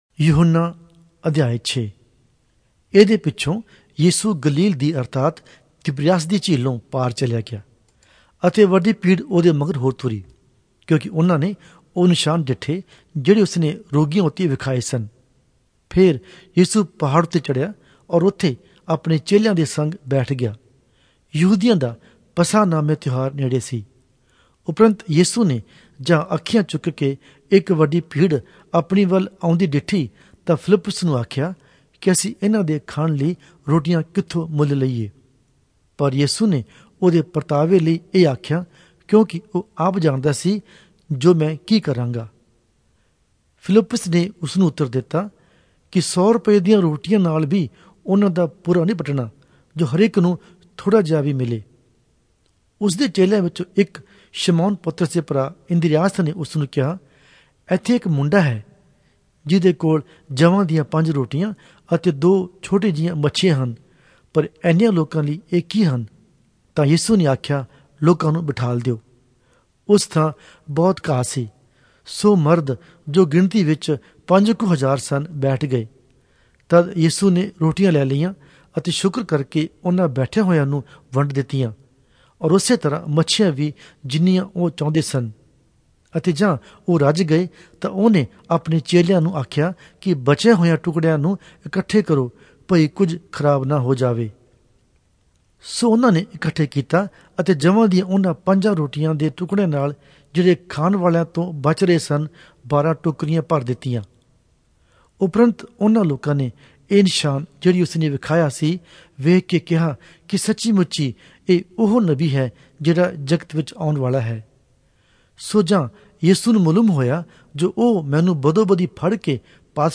Punjabi Audio Bible - John 1 in Bnv bible version